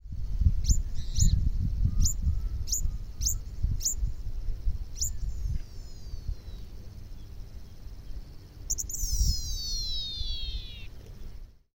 Caminheiro-zumbidor (Anthus chii)
Nome em Inglês: Yellowish Pipit
Fase da vida: Adulto
Localidade ou área protegida: Puerto Olivares
Condição: Selvagem